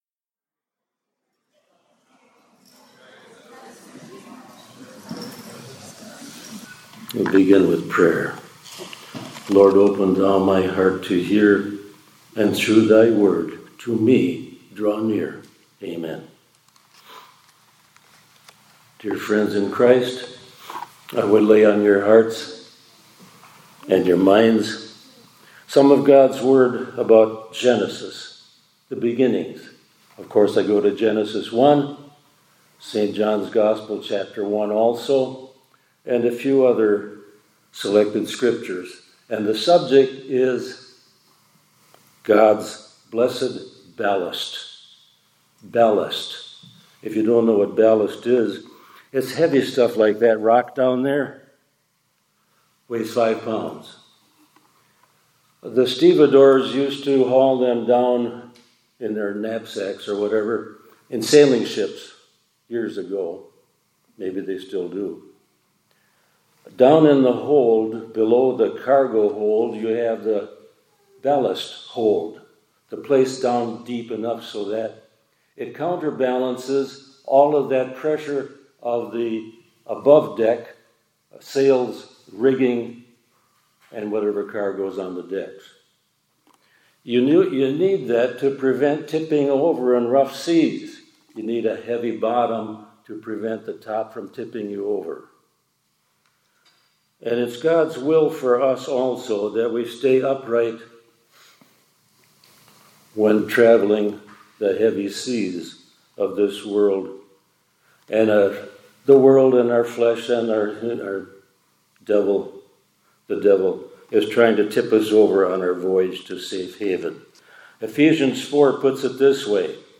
2025-10-02 ILC Chapel — God’s Blessed Ballast